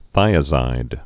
(thīə-zīd, -zĭd)